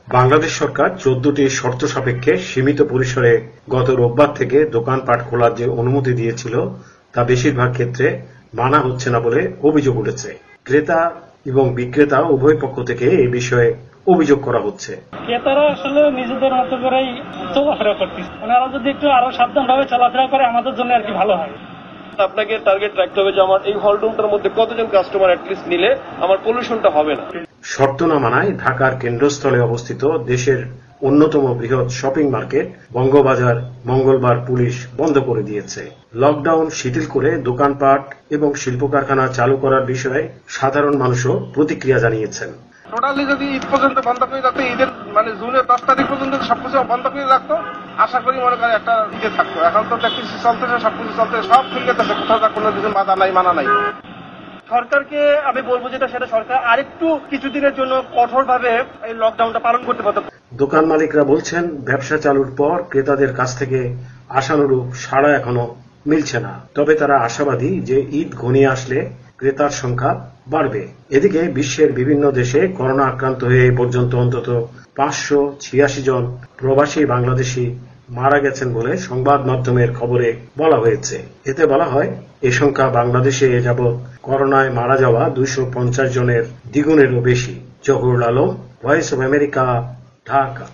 ঢাকা থেকে
রিপোর্ট।